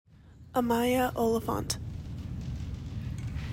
Pronunciation: uh MY uh O lih FONT